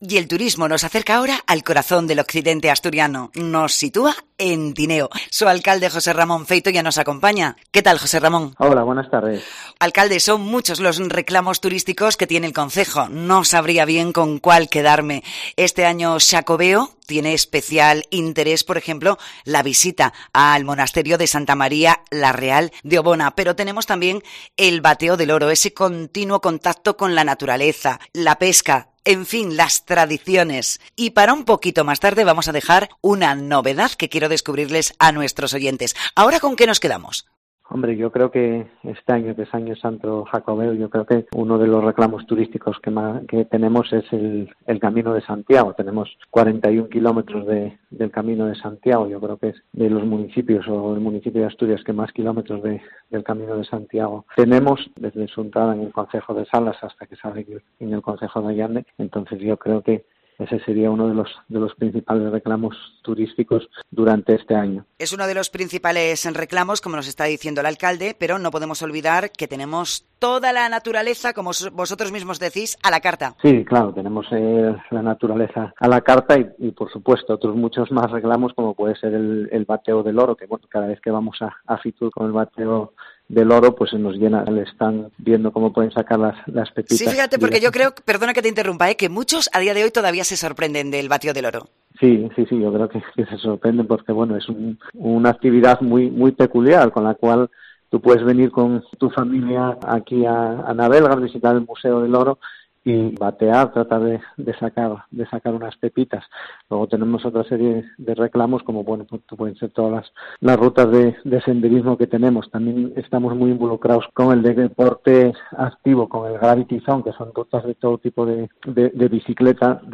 Entrevista al alcalde de Tineo, José Ramón Feito
A todas estas opciones, se suma una semilla turística que va germinando y que pone en contacto al turista con el paisaje y el paisanaje de Tineo: "Son visitas guiadas a explotaciones ganaderas de carne y leche para convivir con los que allí trabajan y hacer lo mismo que ellos", ha explicado Feito en el programa especial de COPE Asturias en FITUR.